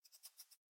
rabbit_idle4.ogg